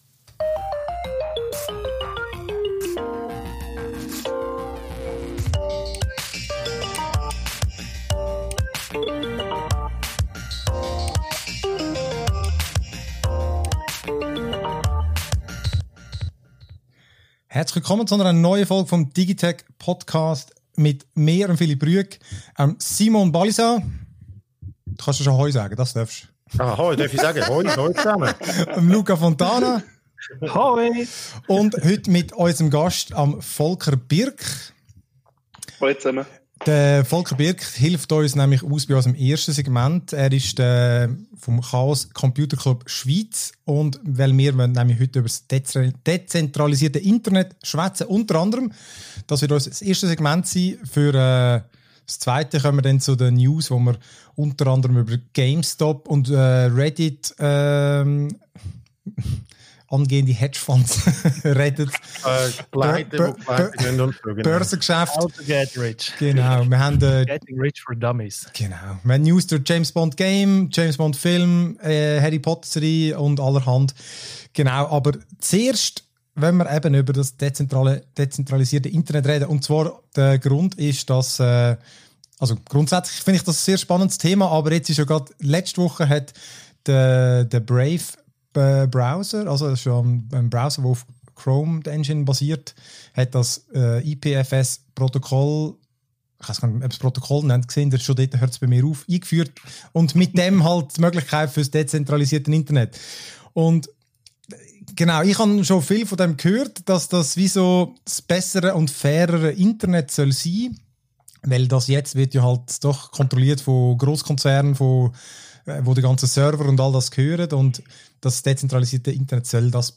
Die Gamestop-Aktie geht durch die Decke, dank dem Effort koordinierter Kleinanleger und zum Leidwesen reicher Wall-Street-Spekulanten (Unsere Aufzeichnung fand vor Börseneröffnung am Donnerstag statt, wir sind also nicht mehr topaktuell). Ausserdem diskutieren wir über «James Bond», eine potentielle «Harry Potter»-Serie und Disney Plus’ «WandaVision».